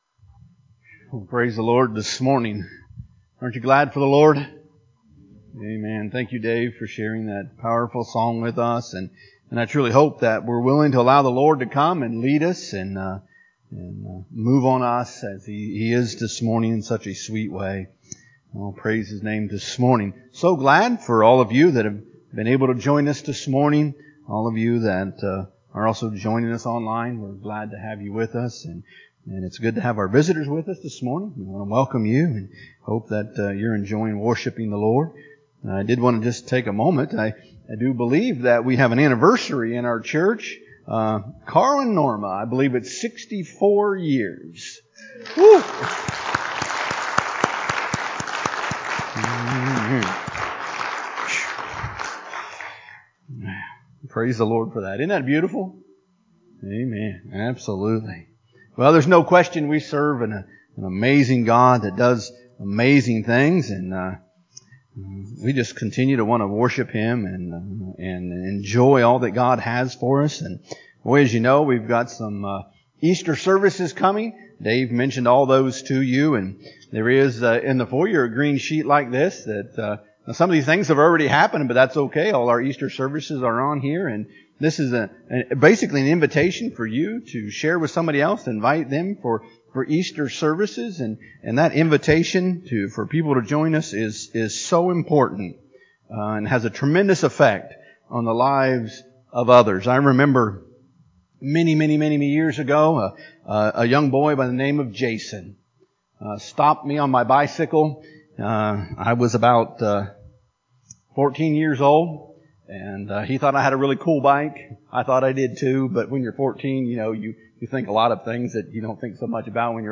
(Link takes you to Facebook, you should not need an account to watch the recording.) admin / Sermons /